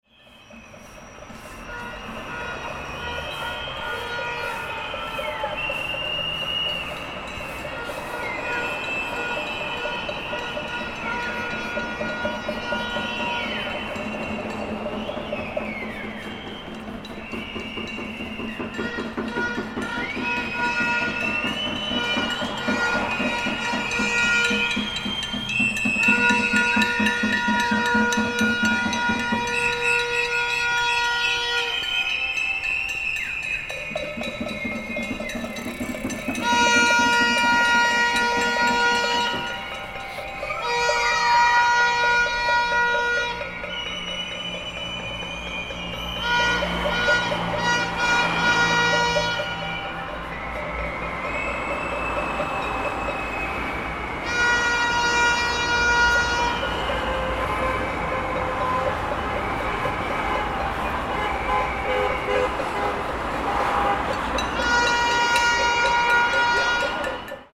Chaotic Street Protest Sound Effect – Whistles, Car Horns, Sirens, Banging
A large, angry crowd loudly protests in an urban environment. The people express their dissatisfaction by creating intense noise and chaotic sounds.
Genres: Sound Effects
Chaotic-street-protest-sound-effect-whistles-car-horns-sirens-banging.mp3